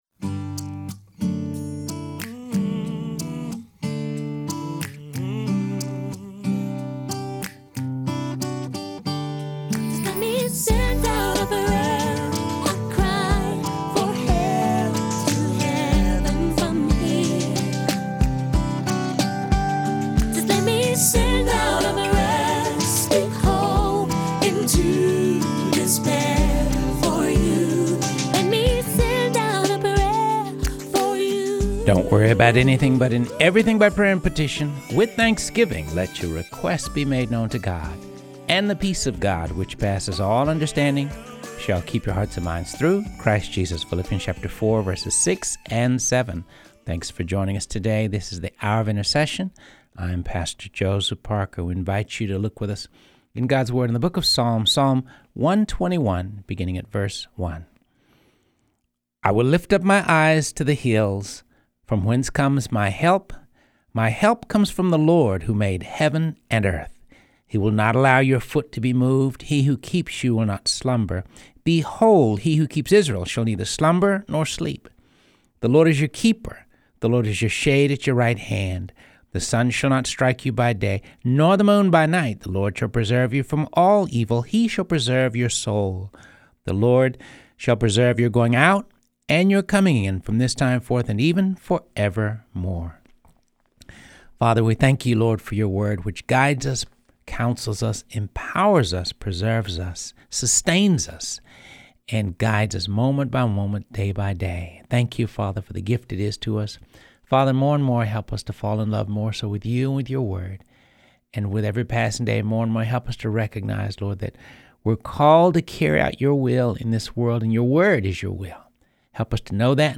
Reading through the Word of God | Episode 104
continues his project of reading through the Bible.